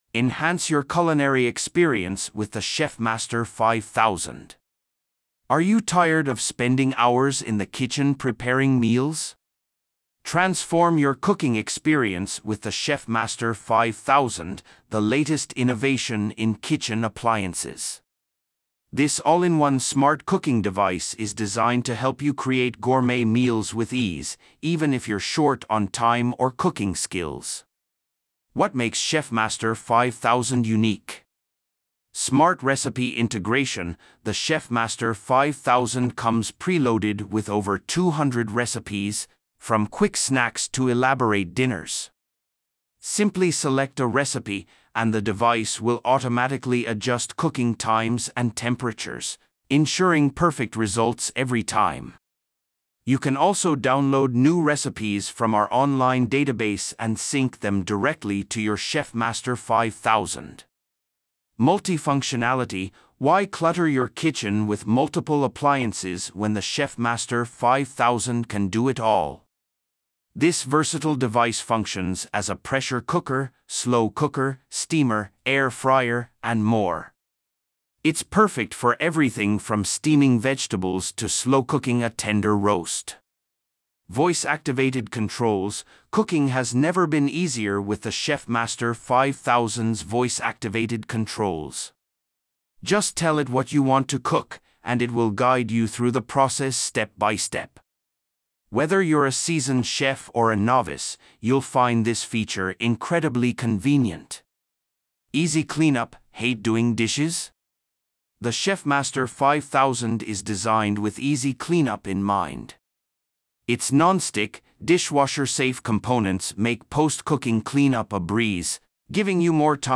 本文読み上げ